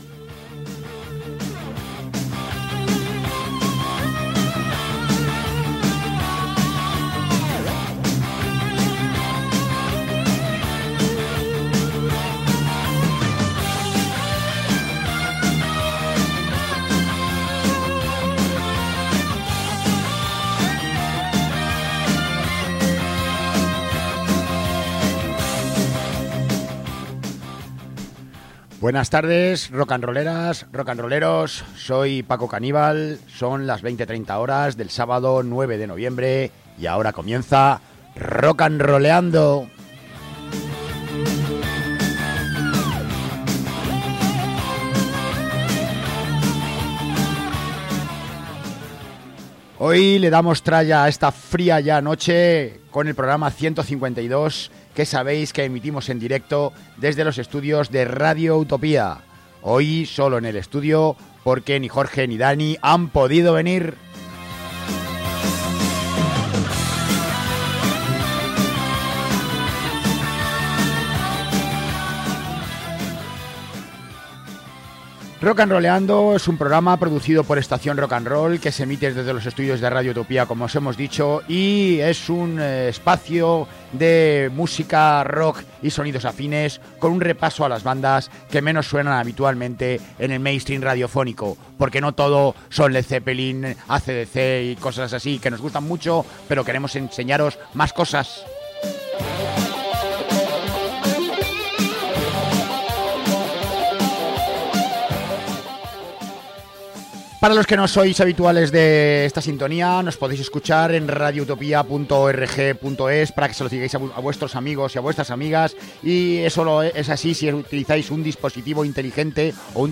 aderezado con armonías que emocionan
repletas de influencias de metal y punk